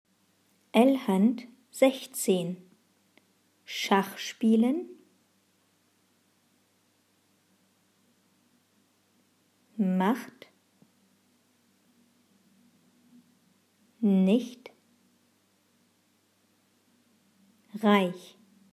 Satz 1 Langsam
L-1-langsam-1.mp3